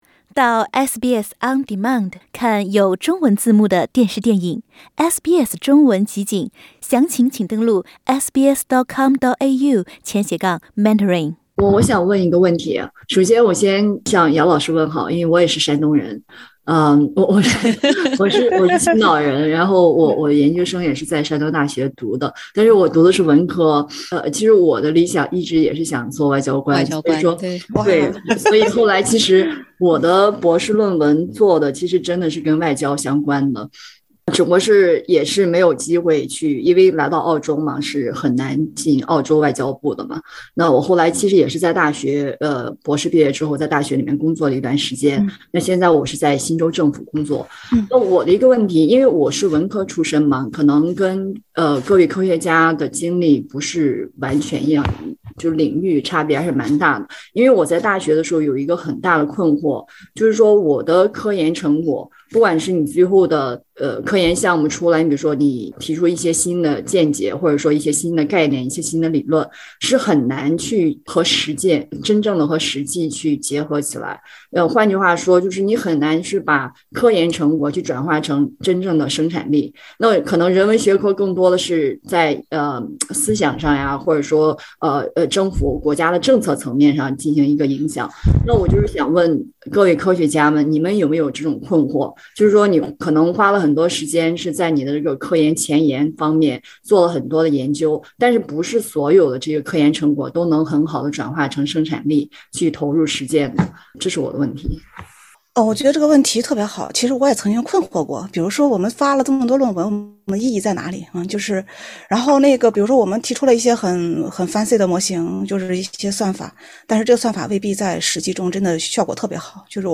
SBS全新谈话类节目《对话后浪》，倾听普通人的烦恼，了解普通人的欢乐，走进普通人的生活。